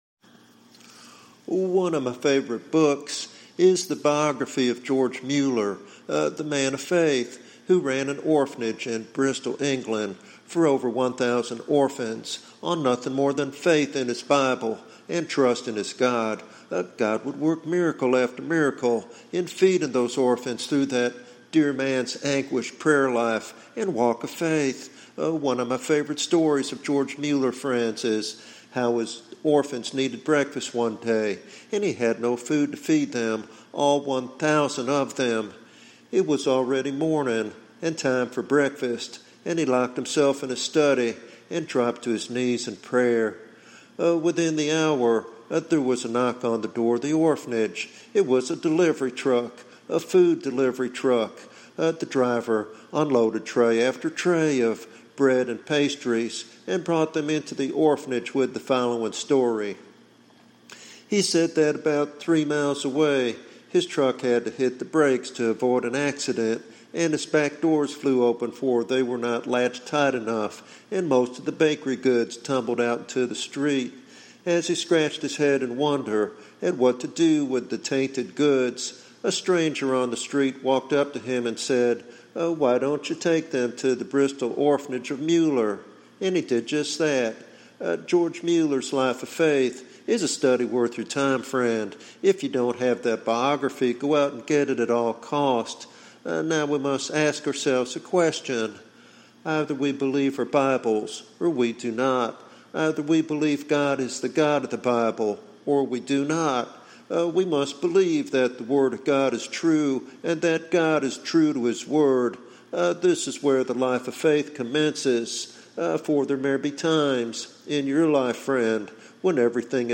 This devotional sermon challenges listeners to deepen their faith and experience God's faithfulness firsthand.